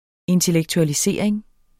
Udtale [ entəlεgtualiˈseˀɐ̯eŋ ]